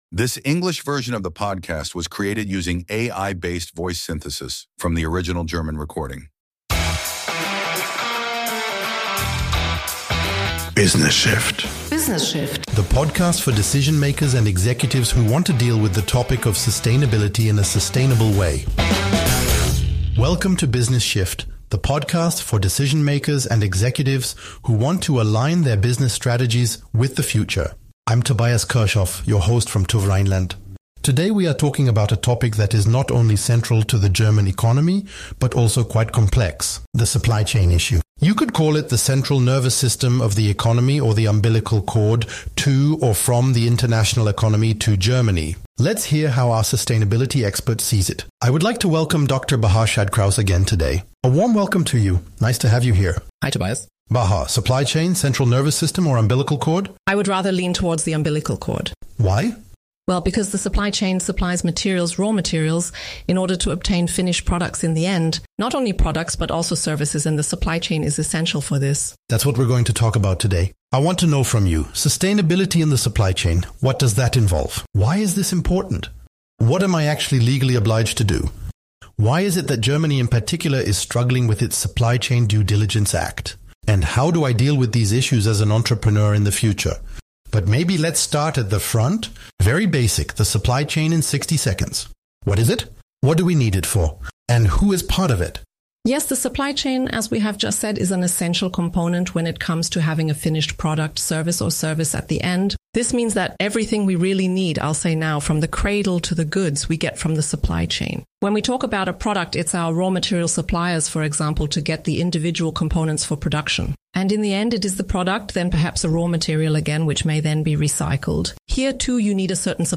***** In compliance with AI regulations, we disclose that the English voices in this podcast episode were generated using artificial intelligence based on the original German version.